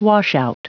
Prononciation du mot : washout